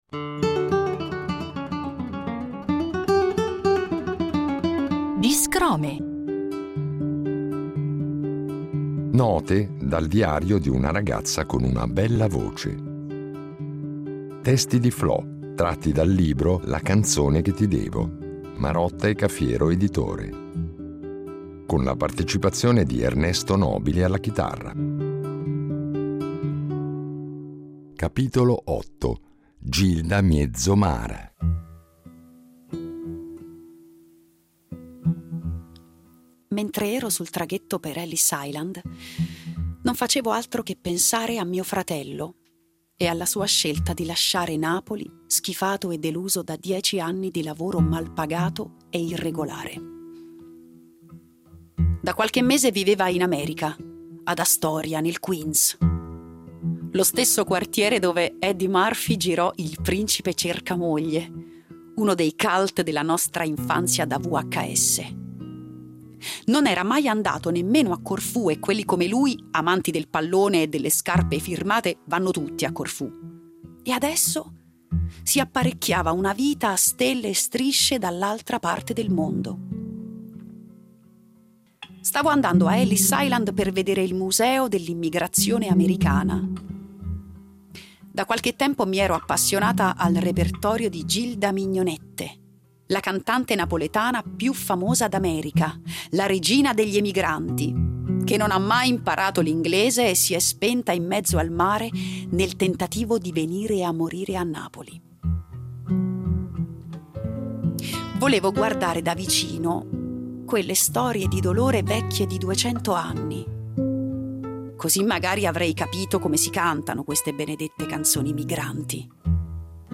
con la voce recitante e cantante